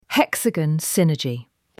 Hek-Suh-Gon Sin-Er-Jee
ElevenLabs_Text_to_Speech_audio.mp3